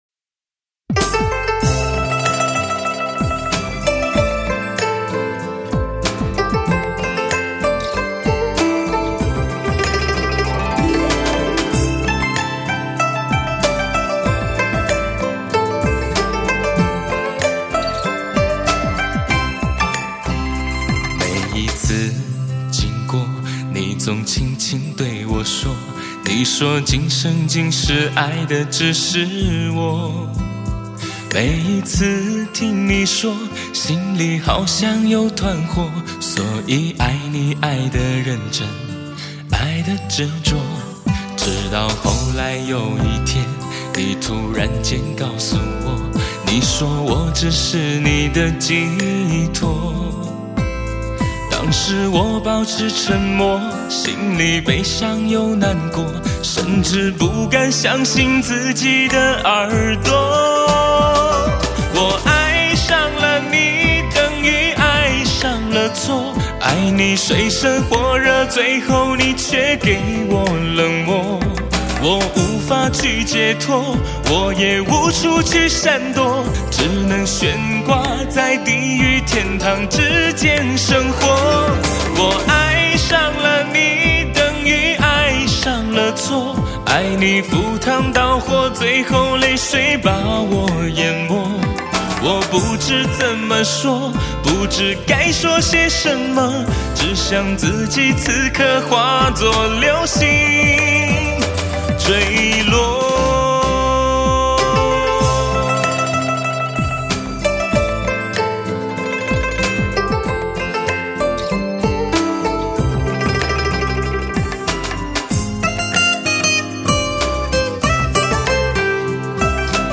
Surround7.1美国技术STS三维高临场音效，
美国最新技术高清录音紫水晶CD。
STS Magix Virtual Live高临场感CD！